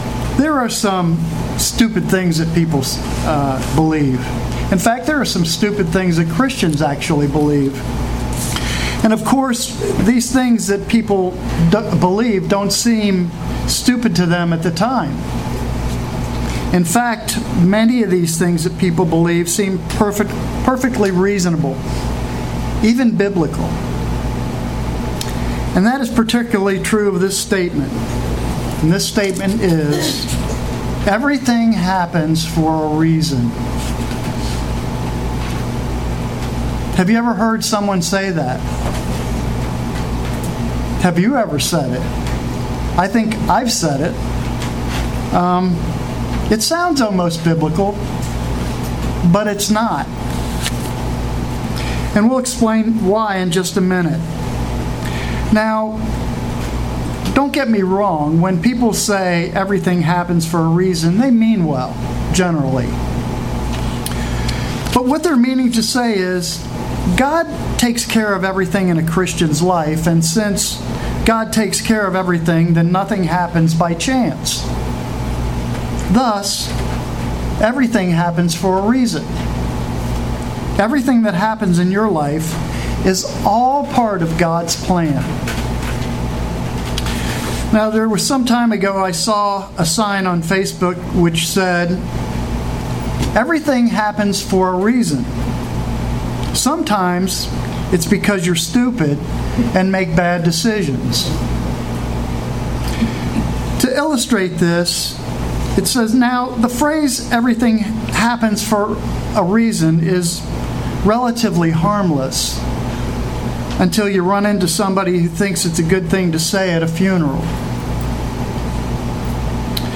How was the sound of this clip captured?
Given in Cincinnati North, OH